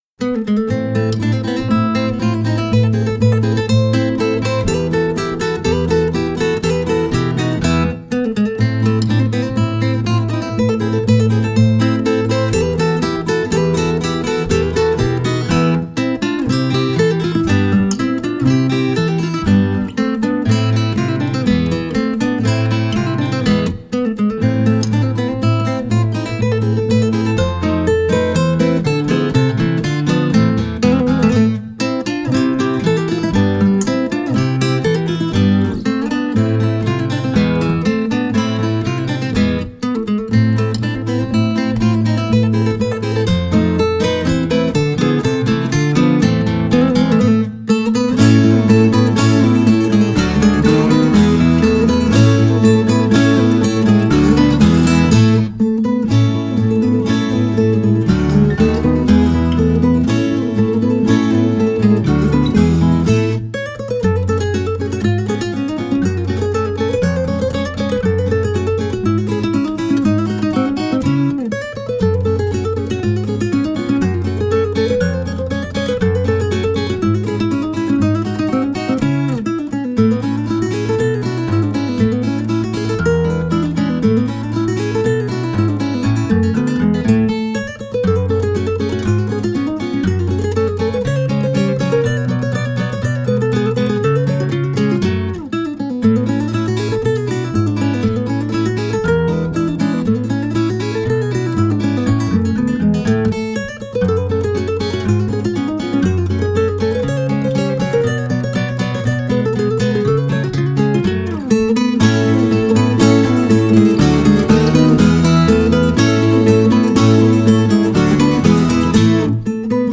The album is smooth jazz